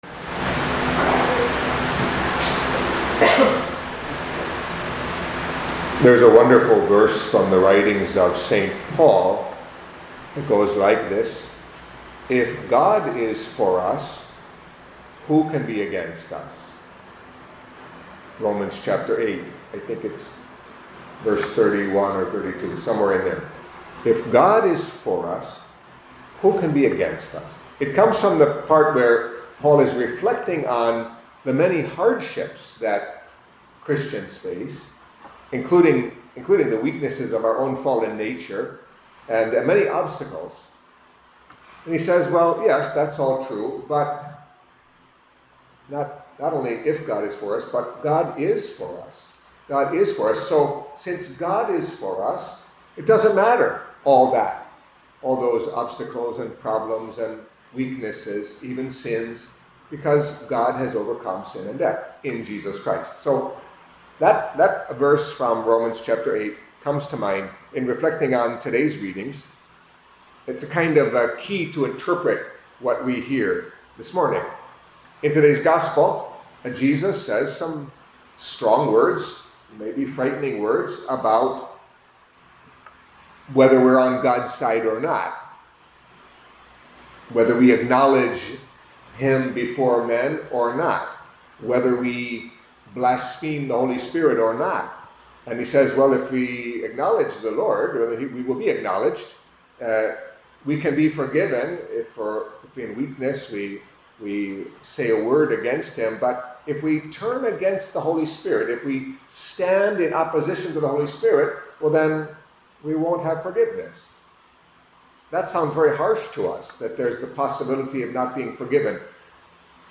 Catholic Mass homily for Saturday of the Twenty-Eight Week in Ordinary Time